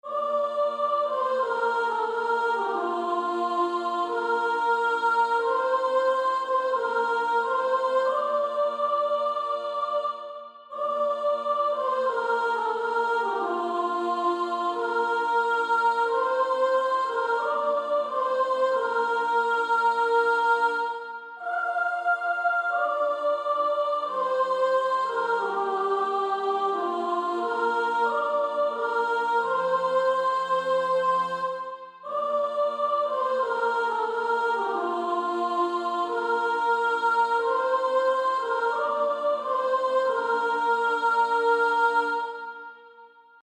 Practice then with the Chord quietly in the background.